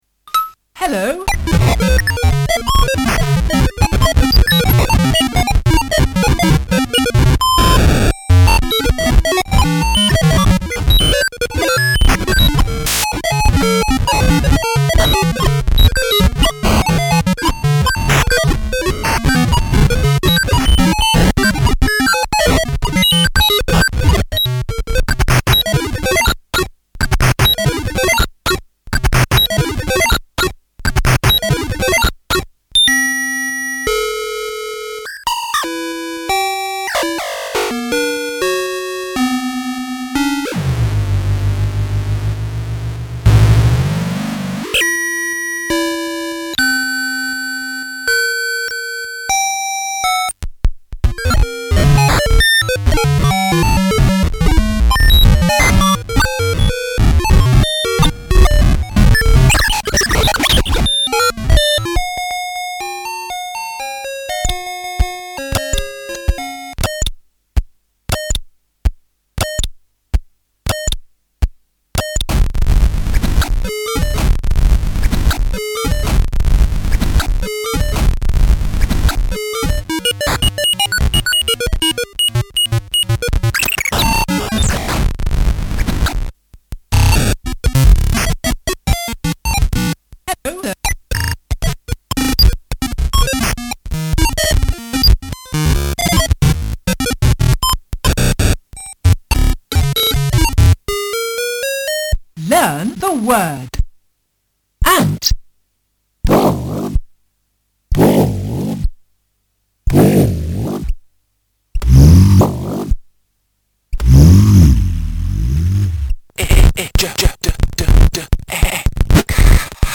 dance/electronic
Leftfield/noise
IDM